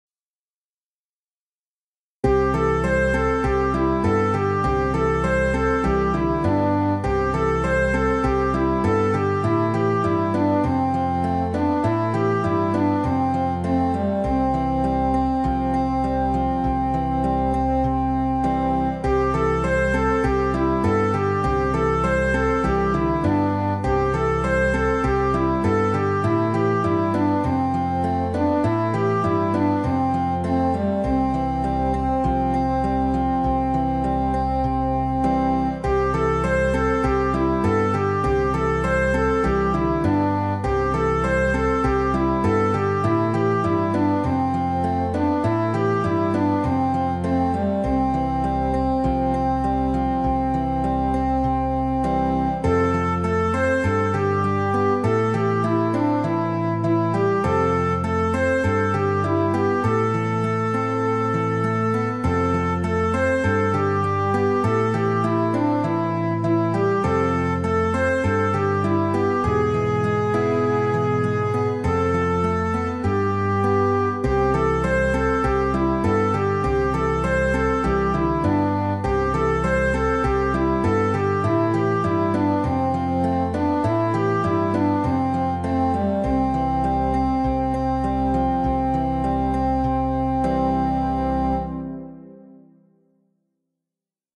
Psalm 103 (V2) Praise the Lord. An appalachian melodic praise to God for his love, mercy, grace, compassion.